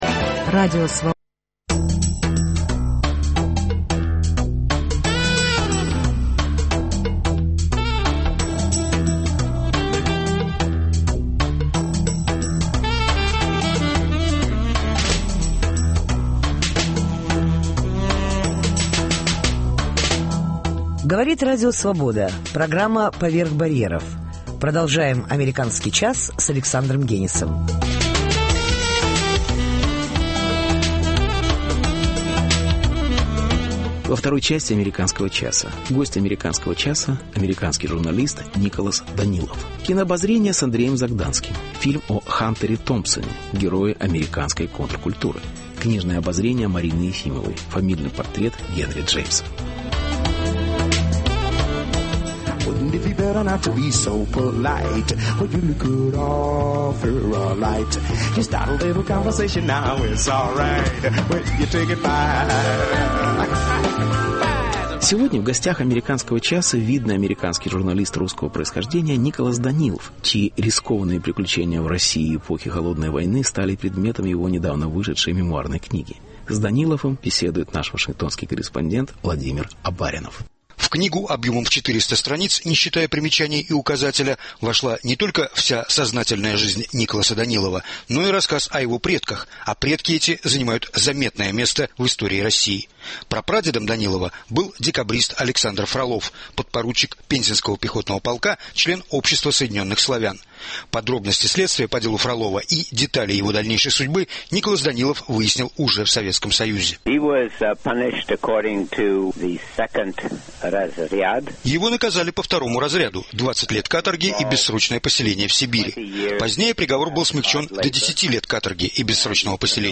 Интервью. Гость «АЧ» - американский журналист